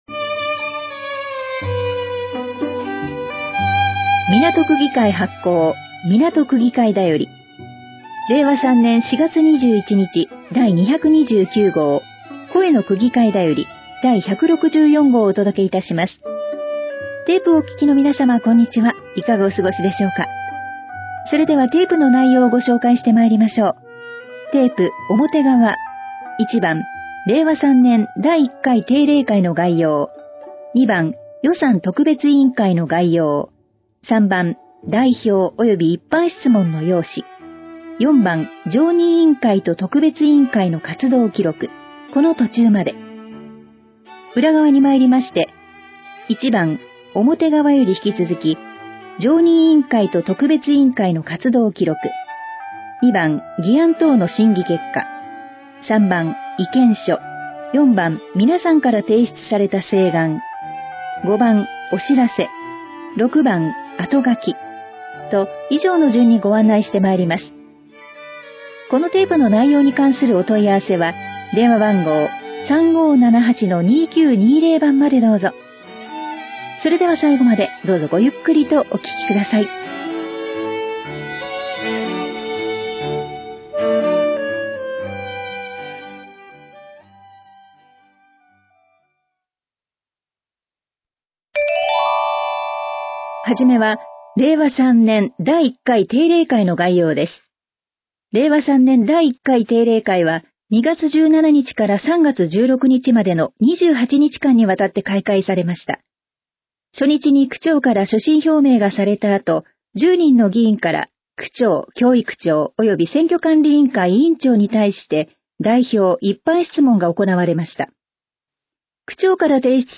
掲載している音声ファイルは、カセットテープで提供している音声ファイルをそのまま掲載しています。そのため、音声の冒頭で「テープの裏側にまいりました」のような説明が入っています。